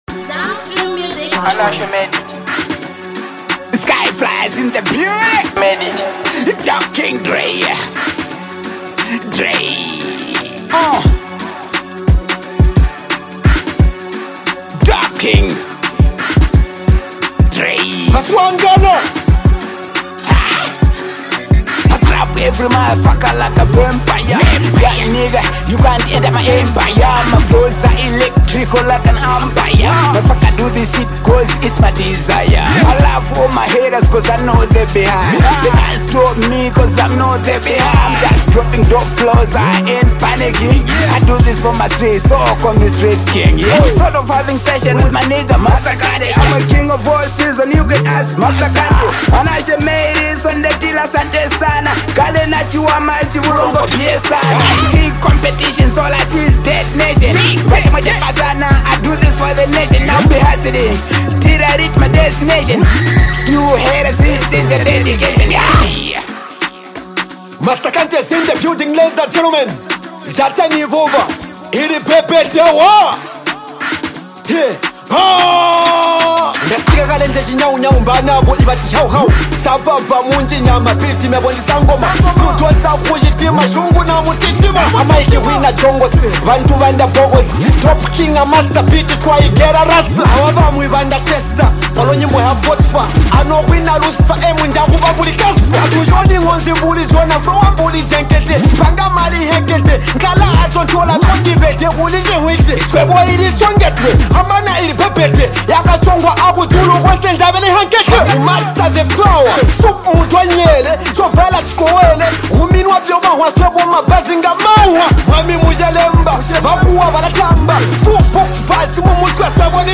vibrate Freestyle